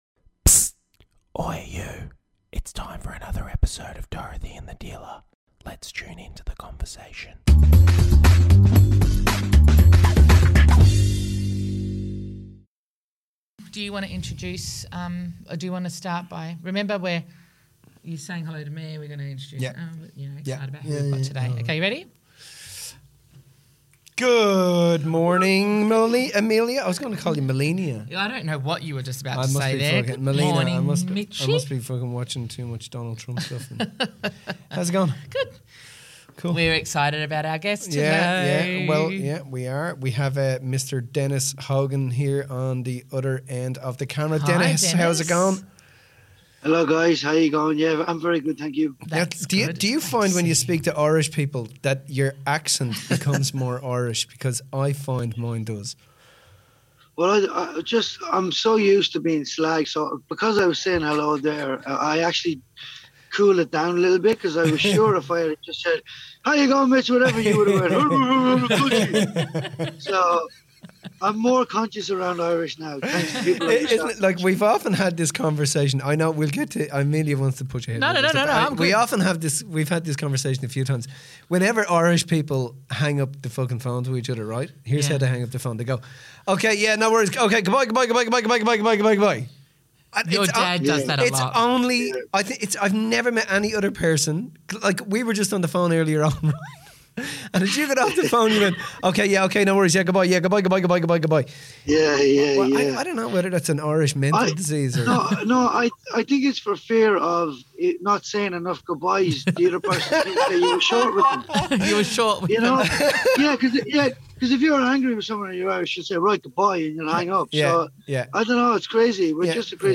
special guest and professional boxer, Dennis Hogan